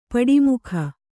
♪ paḍi mukha